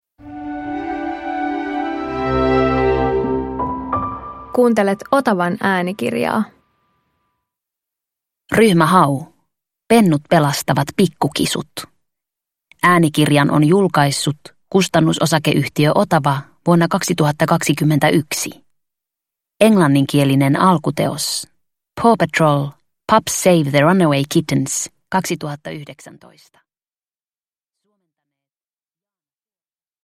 Ryhmä Hau - Pennut pelastavat pikkukisut – Ljudbok – Laddas ner